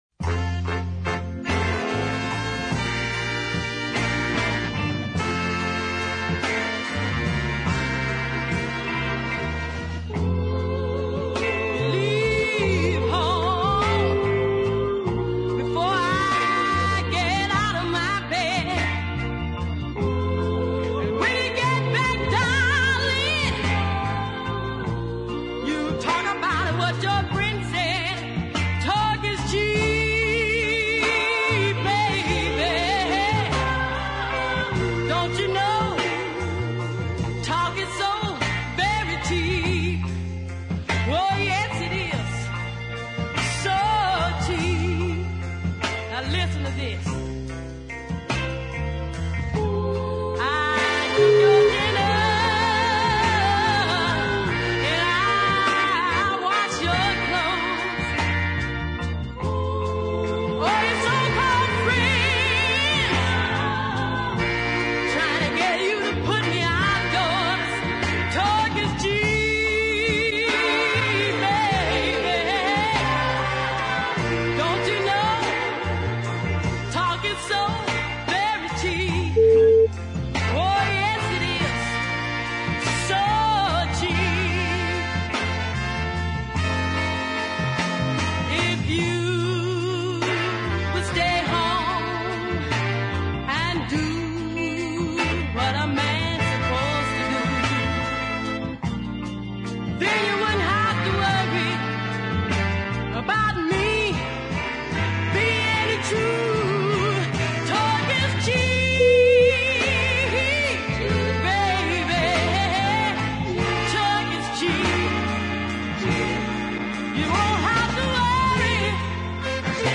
Full of confidence